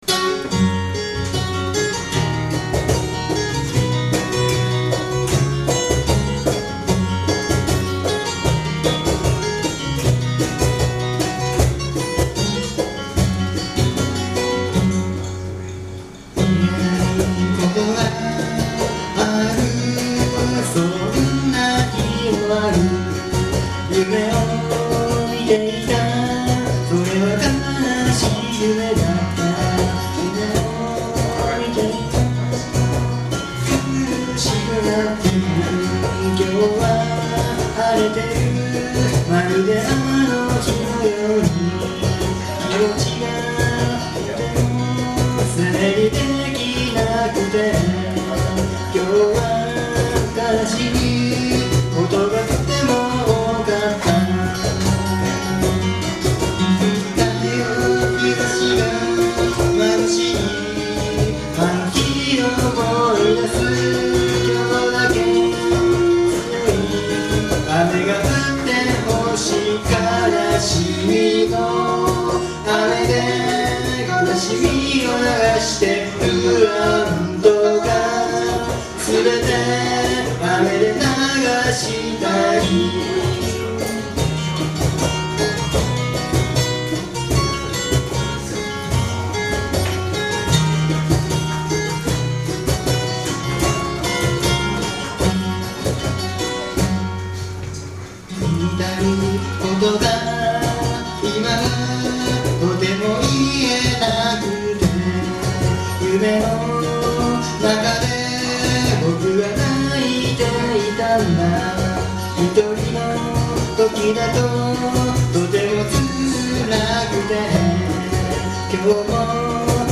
Bluegrass style Folk group
Key of F#→G#
録音場所: 風に吹かれて(大森)
ボーカル、ギター
コーラス、ベース
カホン